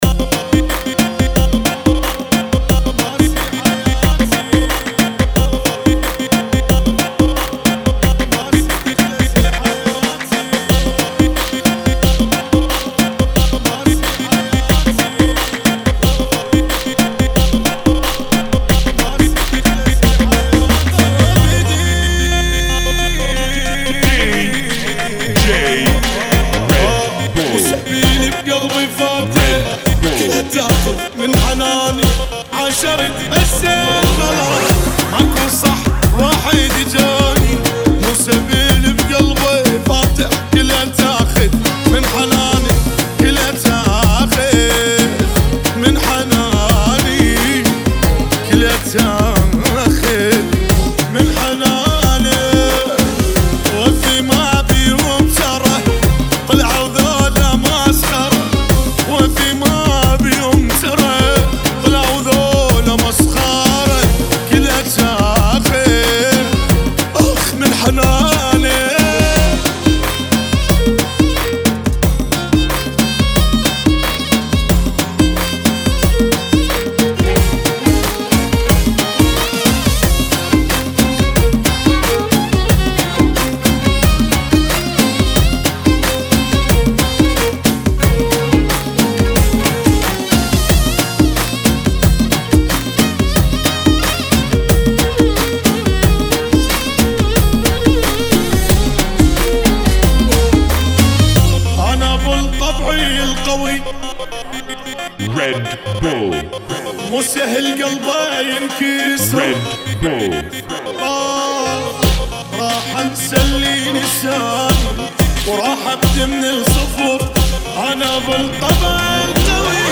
90 bpm